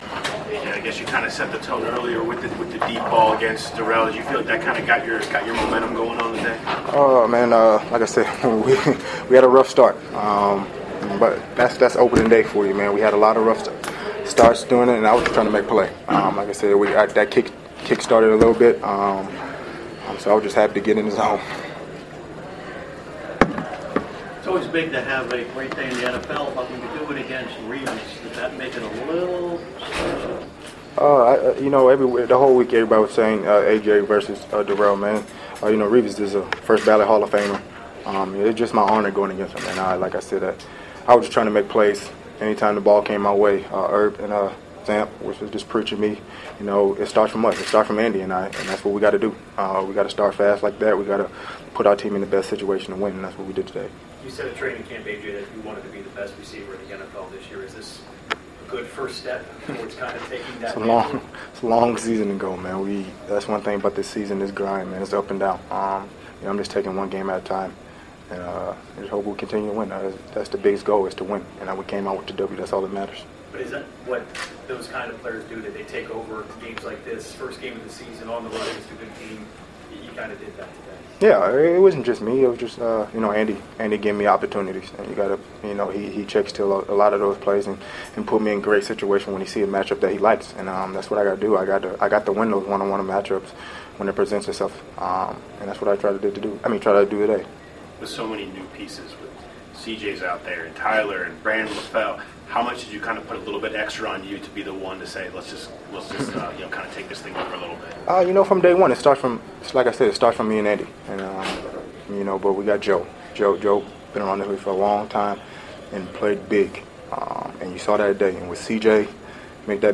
A.J. Green speaks with reporters about his 12-receptions, 180-yard day against Darrelle Revis in New York for the season opener.